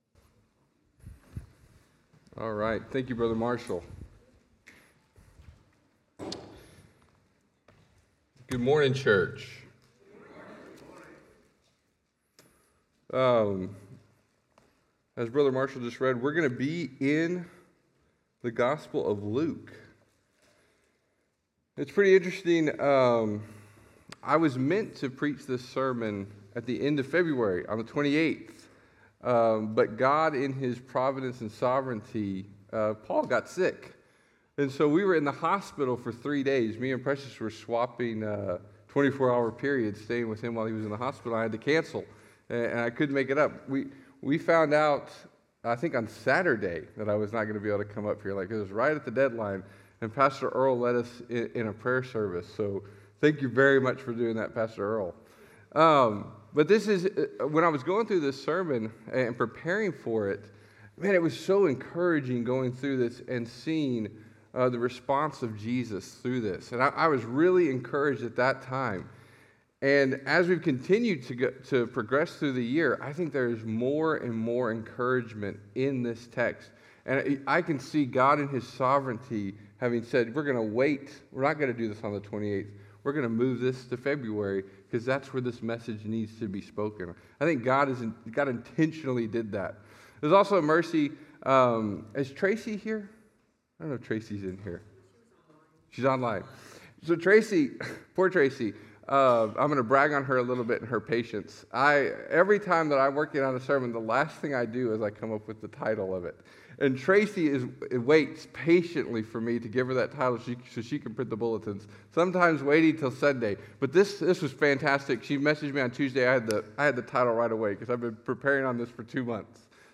Sermon Audio Only